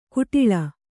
♪ kuṭiḷa